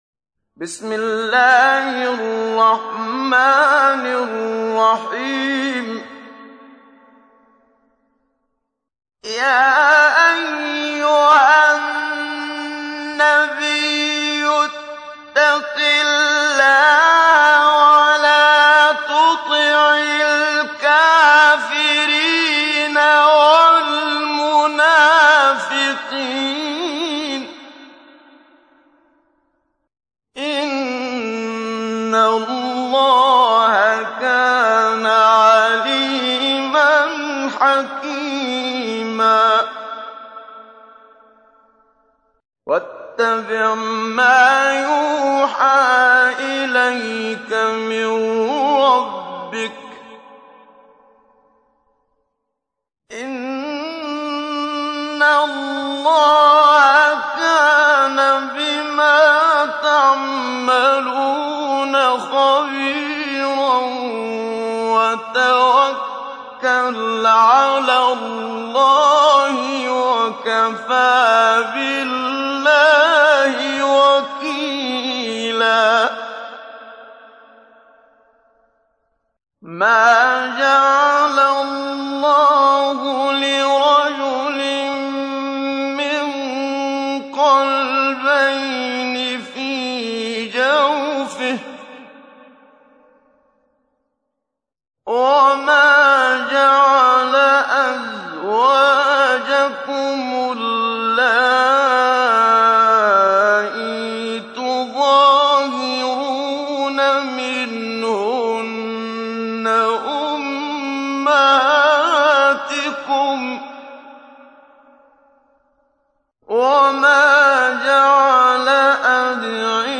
تحميل : 33. سورة الأحزاب / القارئ محمد صديق المنشاوي / القرآن الكريم / موقع يا حسين